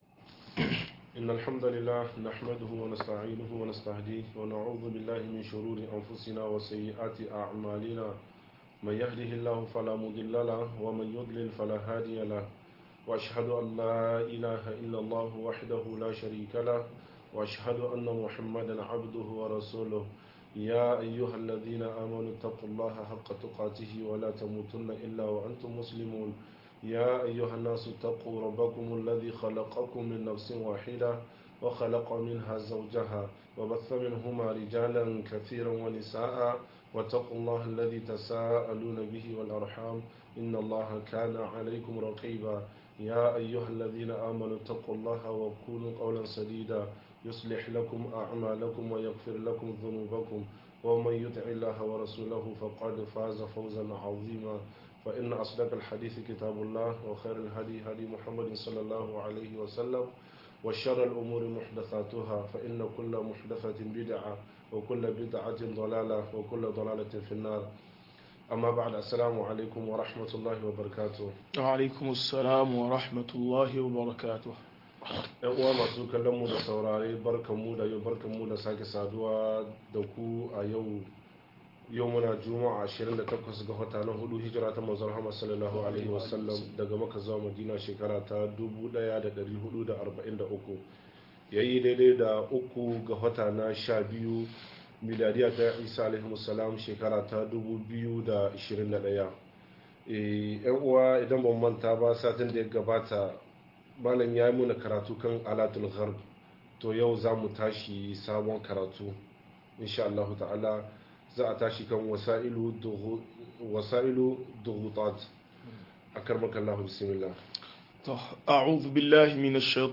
Hanyoyin tilasta al'umma - MUHADARA